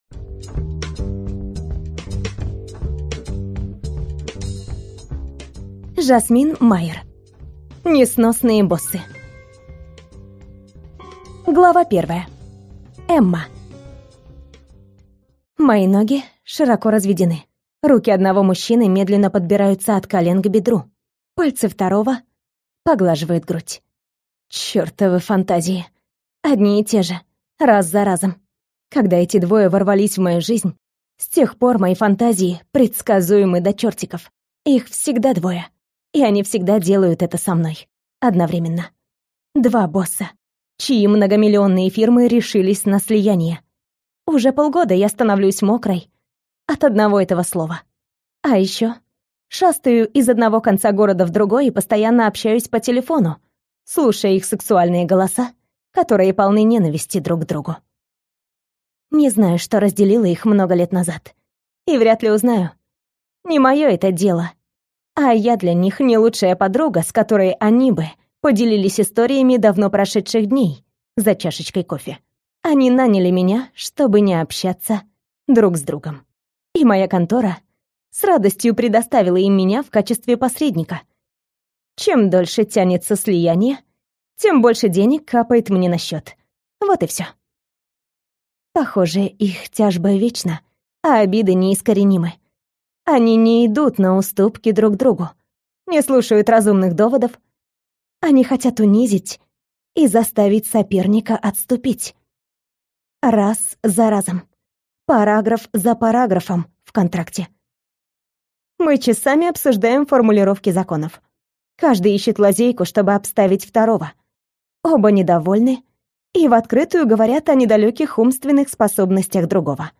Аудиокнига Несносные боссы | Библиотека аудиокниг